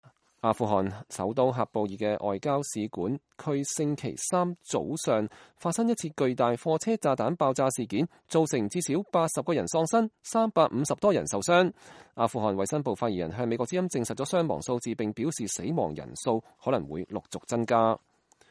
喀布爾使館區發生巨大貨車炸彈爆炸事件，一名現場的男子驚恐不安